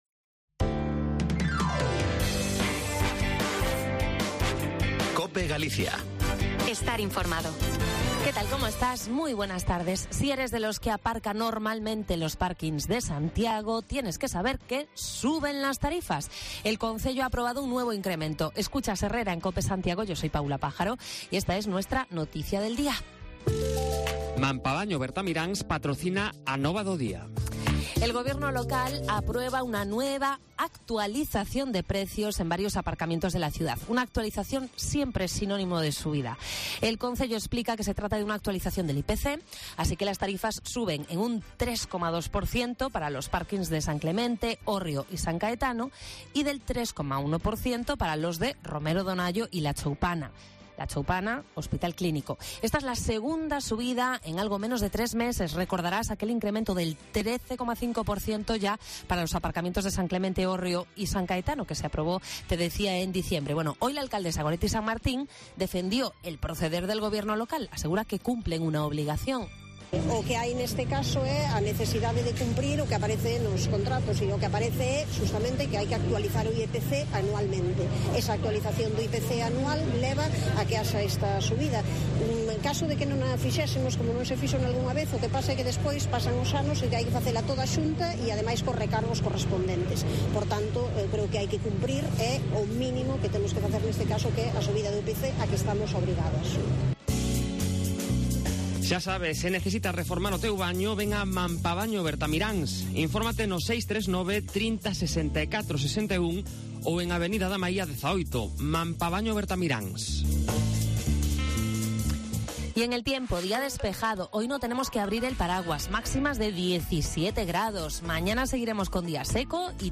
Santiago - A Estrada en 10 minutos y sin peajes: así será el trayecto por carretera cuando entre en servicio la totalidad de la autovía AG-59. Hacemos ese recorrido hoy en día, con paradas para hablar con vecinos y comerciantes de la Ramallosa, Pontevea o A Estrada.